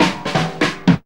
JAZZ FILL 1.wav